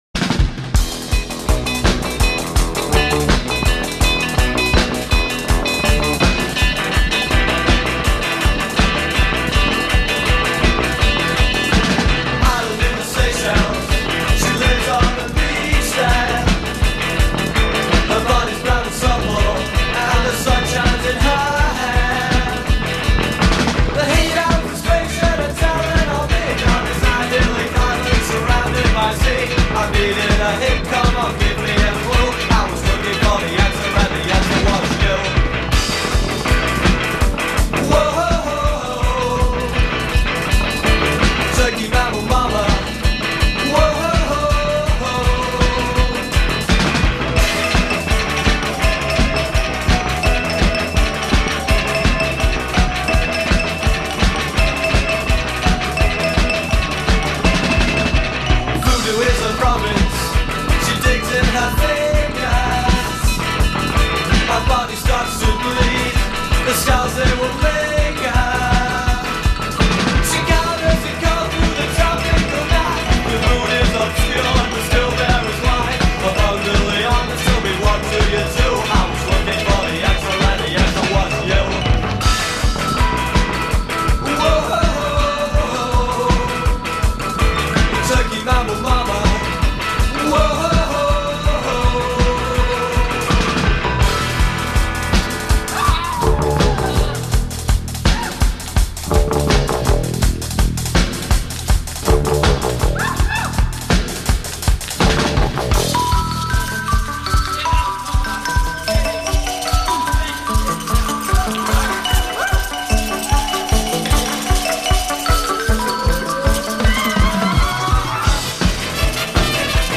More associated with New Wave than Alternative Rock